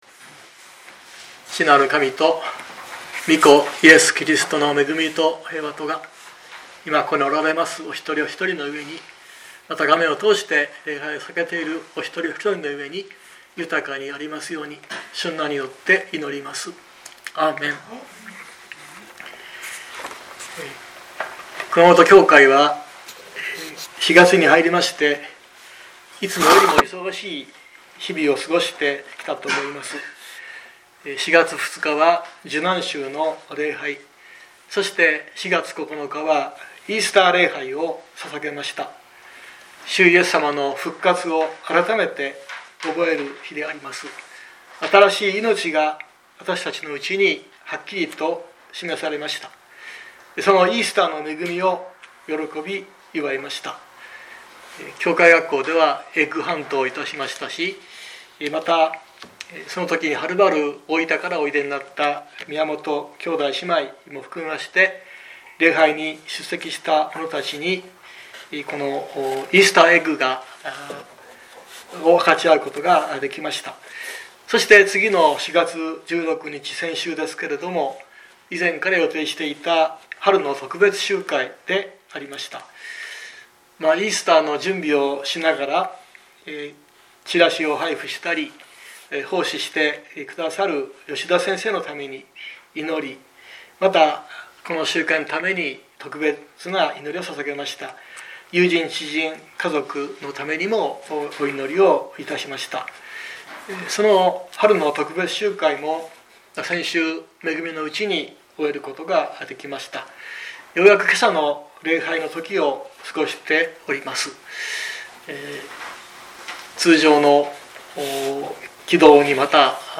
2023年04月23日朝の礼拝「友を起こすために」熊本教会
熊本教会。説教アーカイブ。